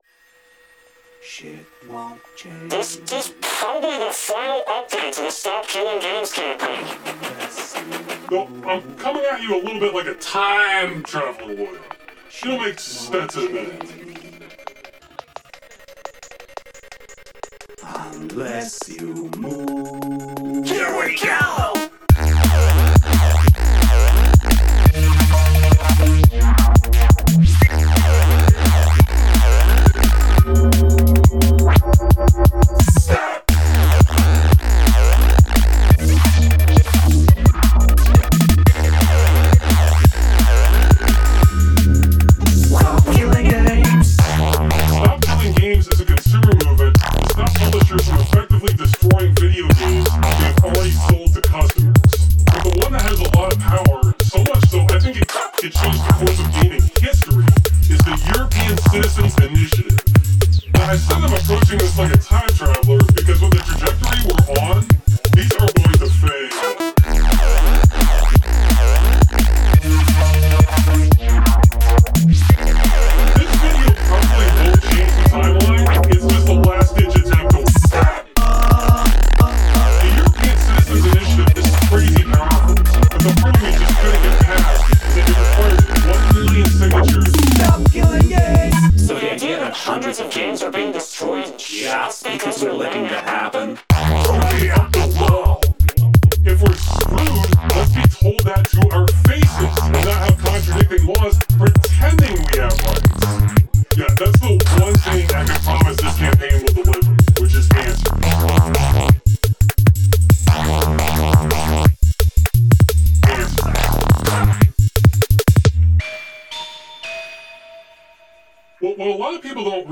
Music made with 100% FOSS software (details in description).
sick track! 🔥